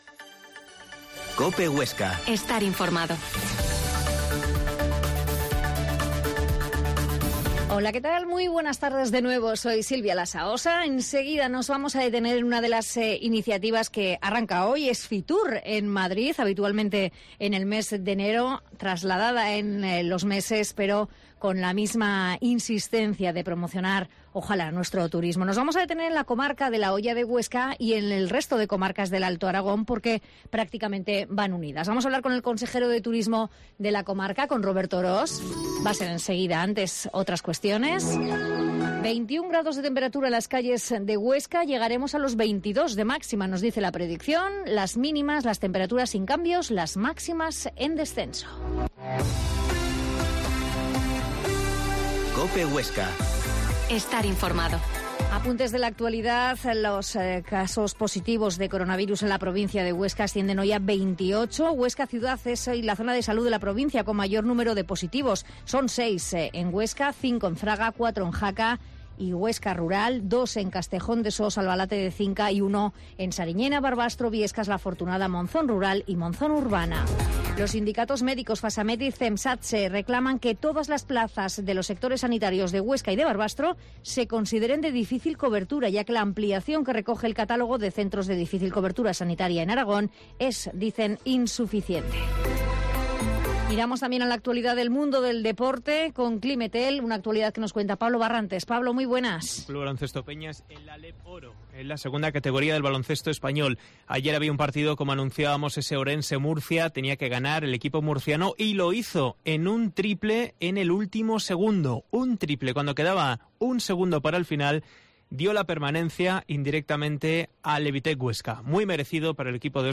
Mediodía en Cope Huesca 13,20h. Entrevista al consejero de Turismo de la Hoya